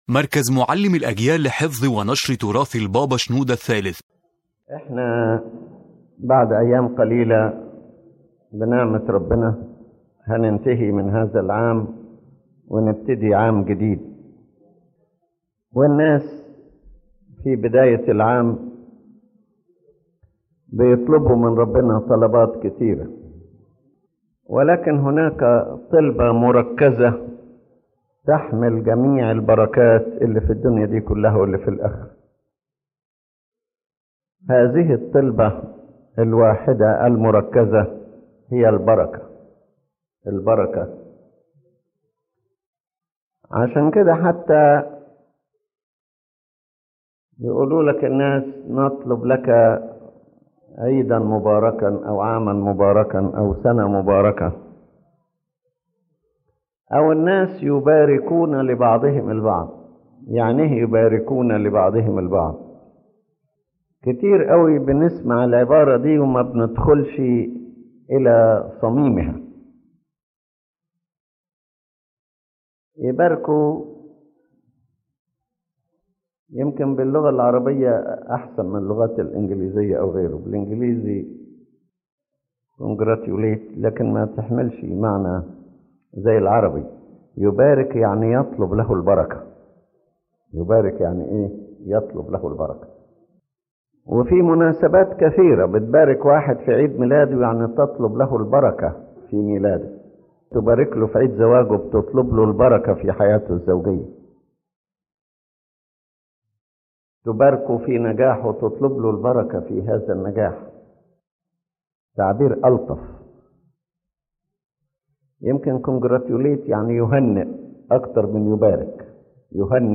Lecture Summary